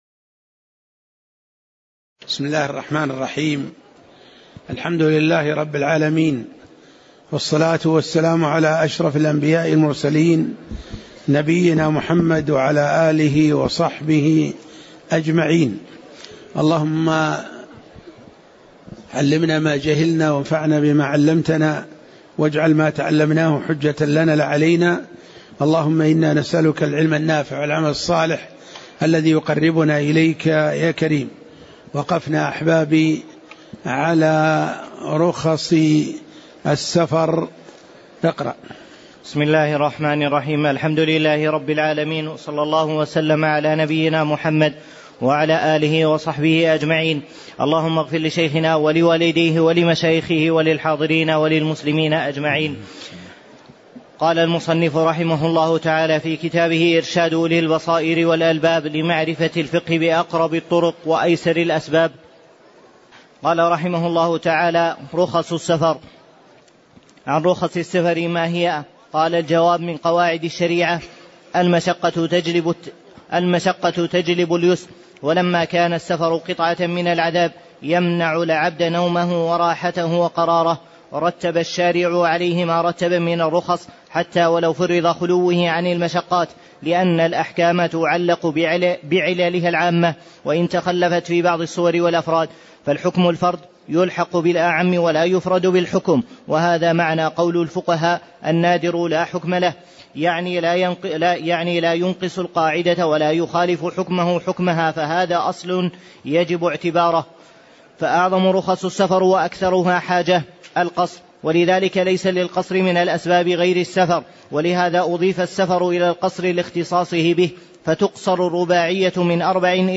تاريخ النشر ١٧ شوال ١٤٣٨ هـ المكان: المسجد النبوي الشيخ